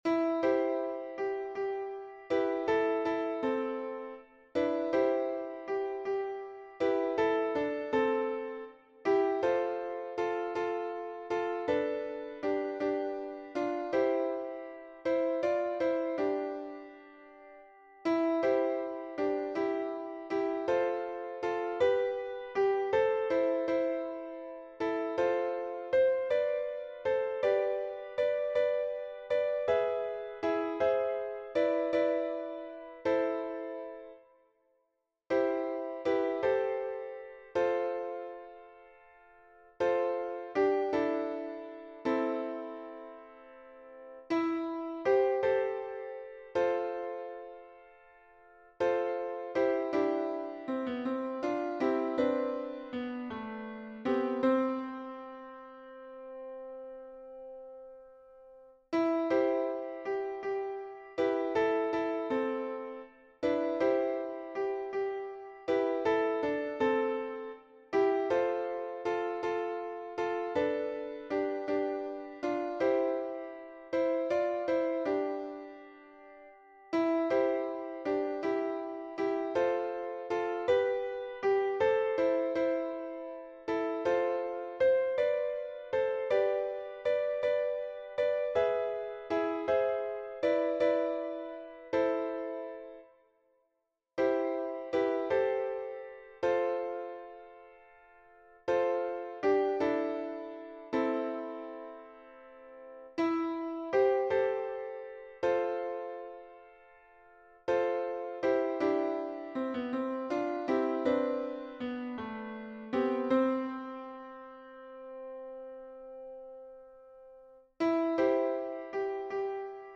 MP3 version piano